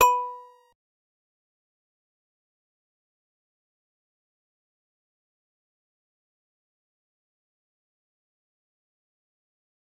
G_Musicbox-B4-pp.wav